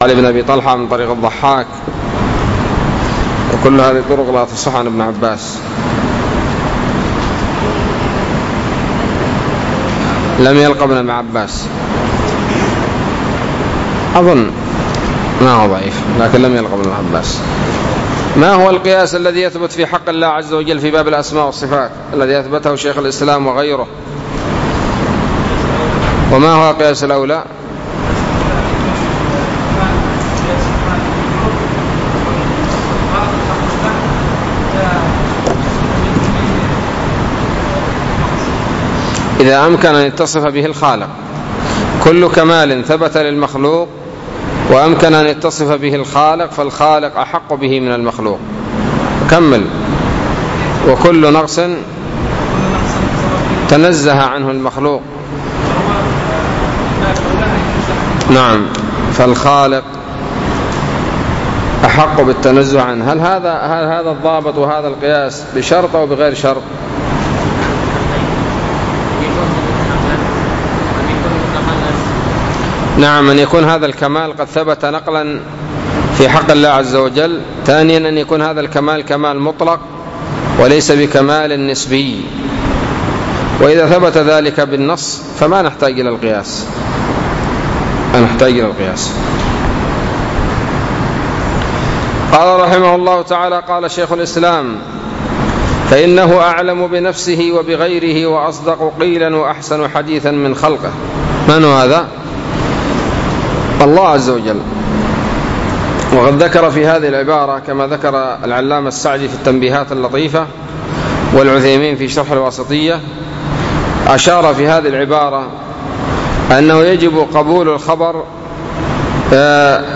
الدرس السادس والعشرون من شرح العقيدة الواسطية